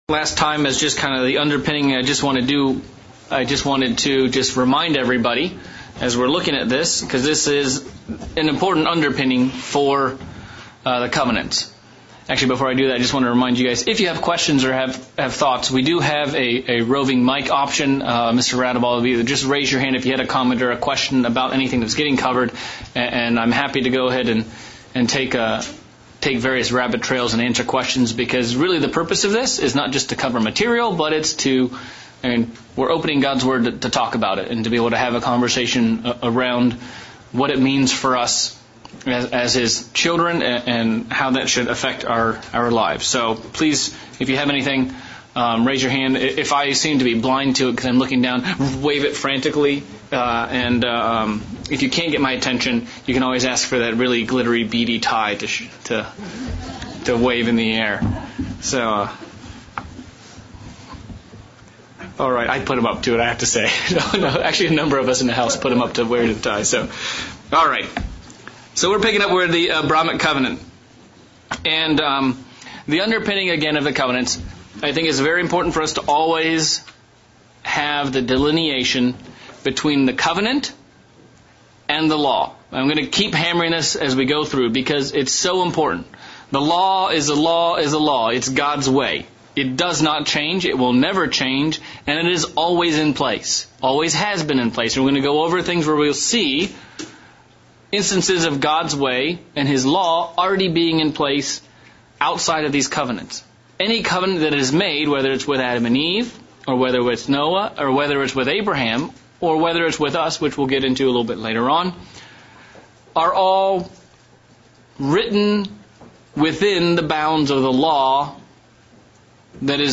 December 2021 Bible Study - Covenants Part 2